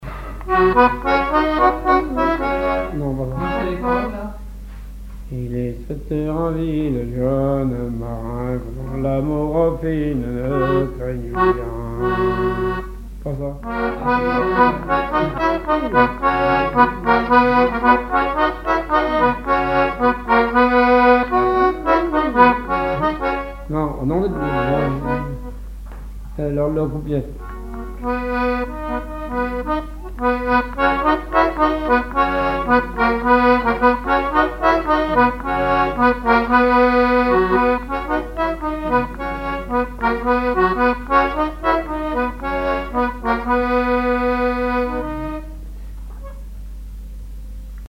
Genre énumérative
accordéon diatonique
Pièce musicale inédite